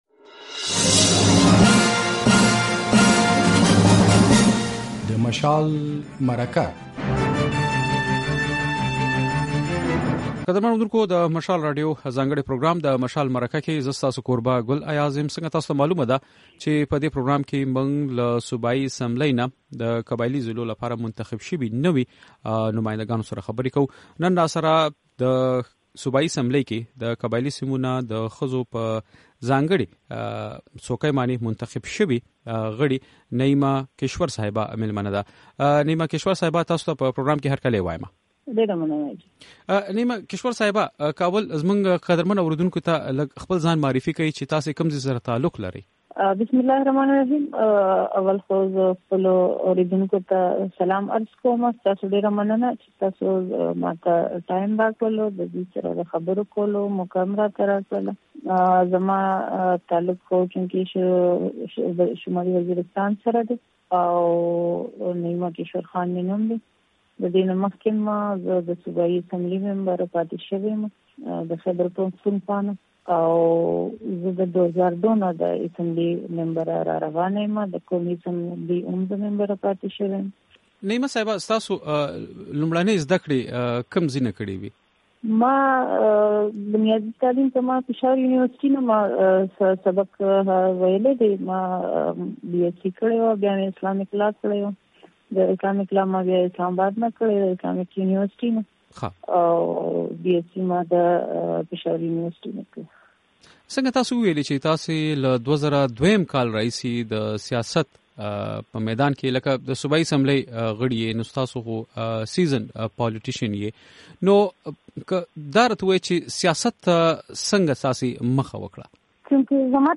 له نعیمه کشور سره د مشال مرکه